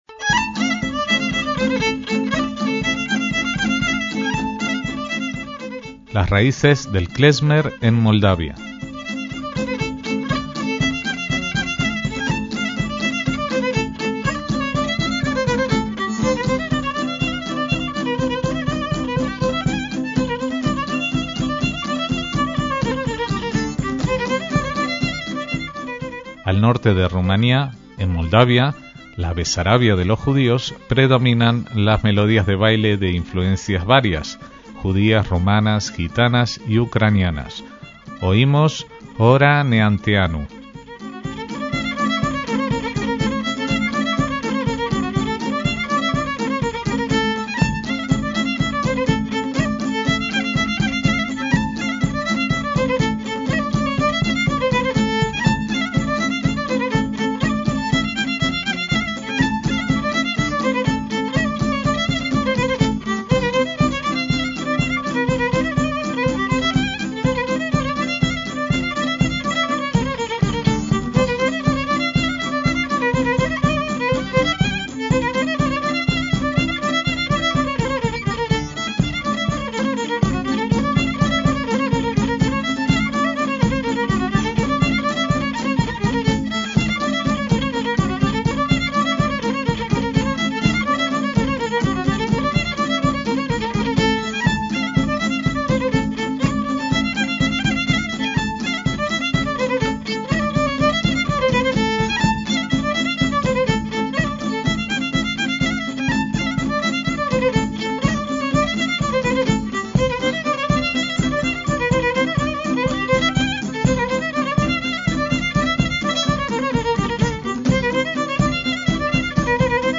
violín
laúd cobza
bombo